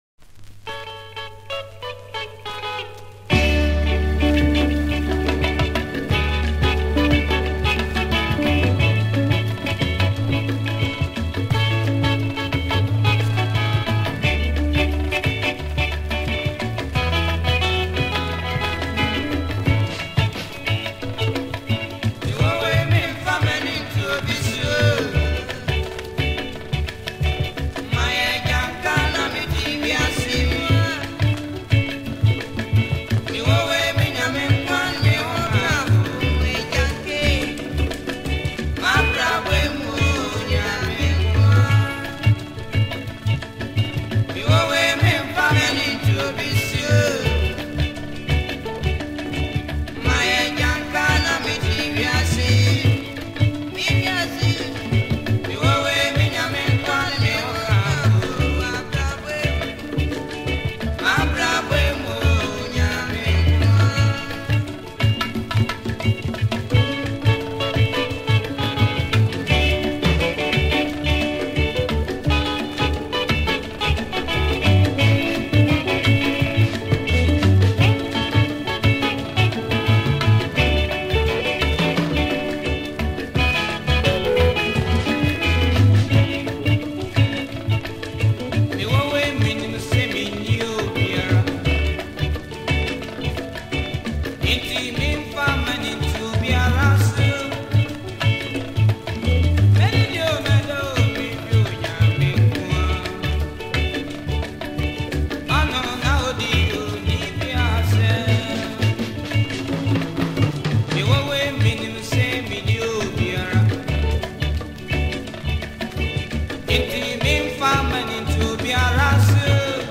an old highlife song